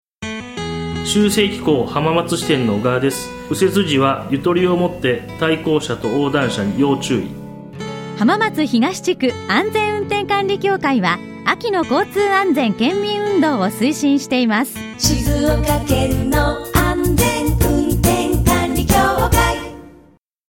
秋の交通安全運動に係わるラジオ広報（Ｋ－ＭＩＸ）。